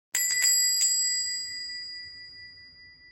door bell.mp3